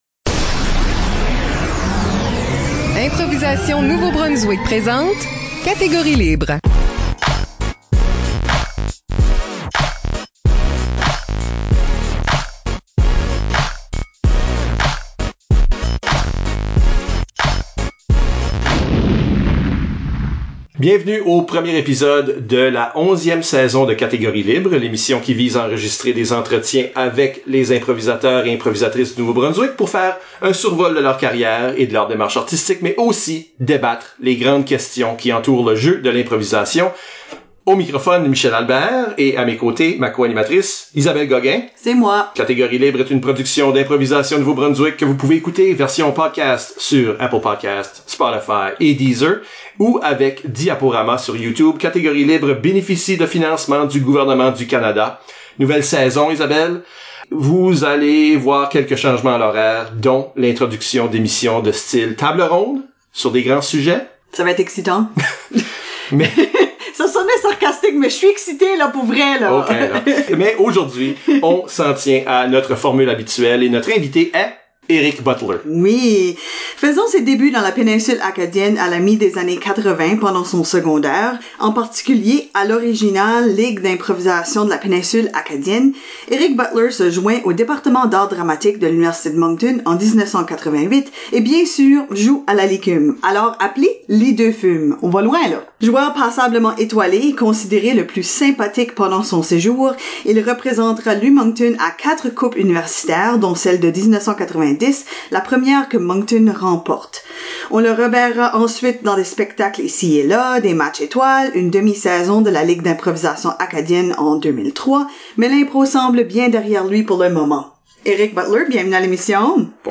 Pour une onzième saison, Catégorie Libre présente une série d’entretiens avec les improvisateurs et improvisatrices de la province pour parler d’eux et des grandes questions qui entourent l’improvisation.